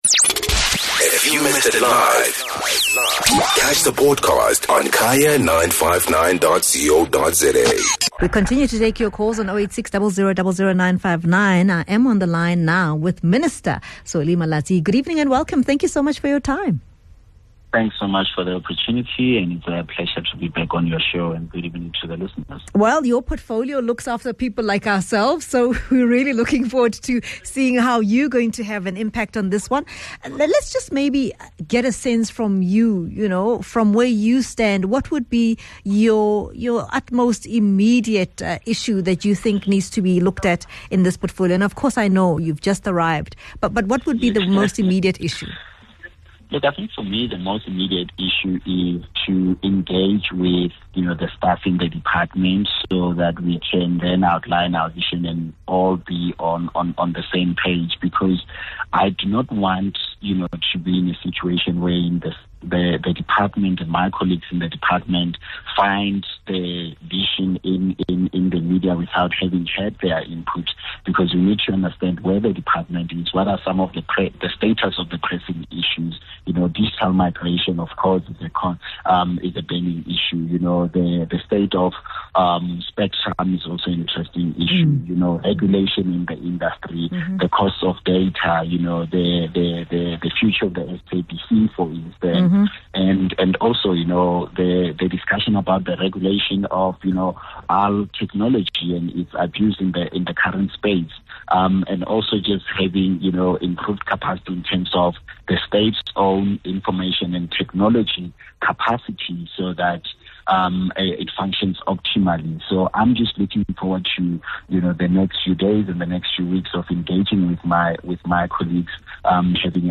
Guest: Solly Malatsi - Newly appointed Comunications Minister from the DA